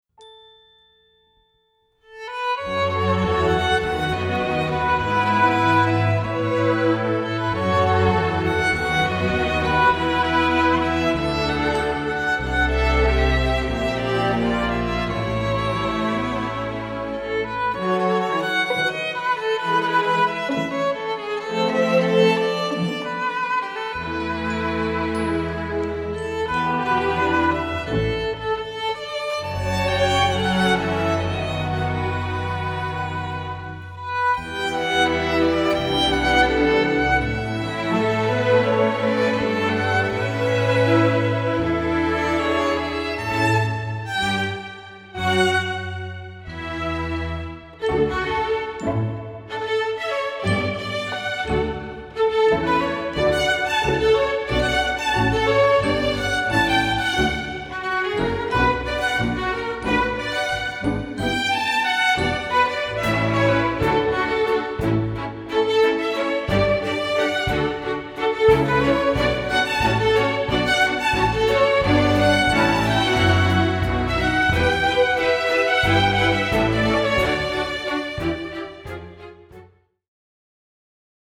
Category: String Orchestra
Instrumentation: str=8.8(4).4.4.4,pf,1 perc,drumset
upbeat and entertaining production number